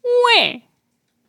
baby gibberish nonsense whiner sound effect free sound royalty free Memes